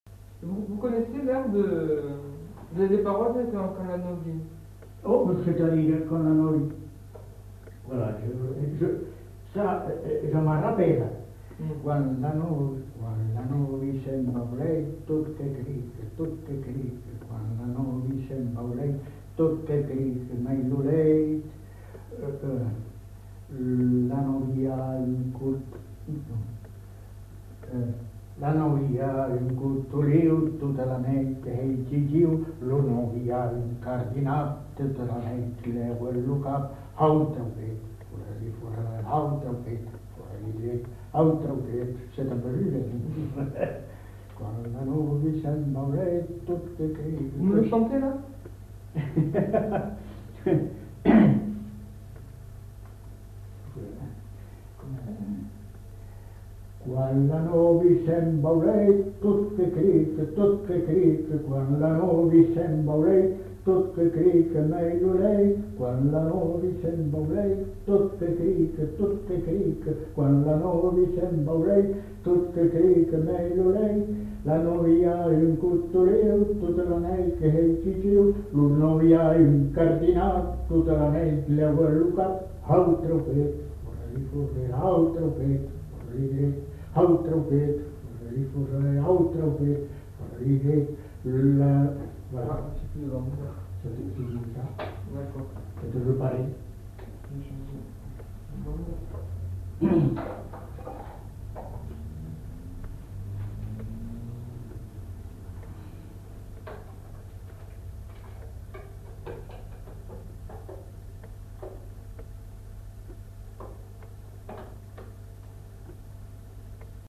Lieu : Bazas
Genre : chant
Effectif : 1
Type de voix : voix d'homme
Production du son : chanté
Danse : rondeau